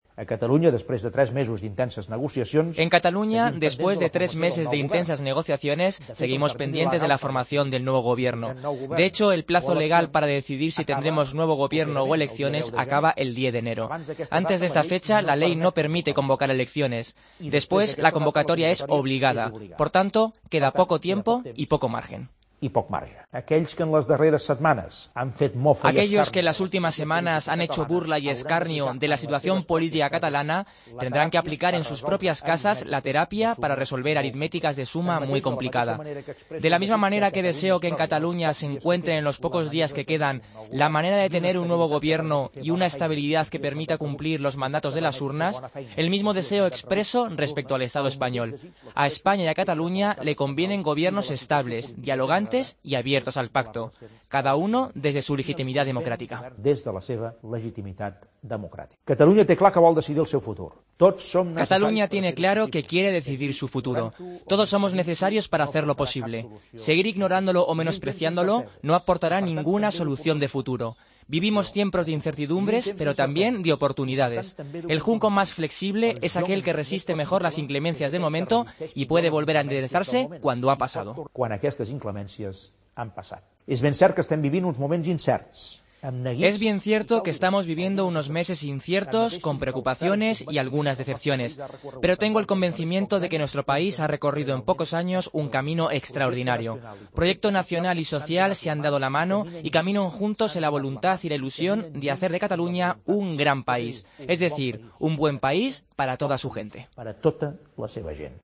Fragmento del discurso de Fin de Año de Artur Mas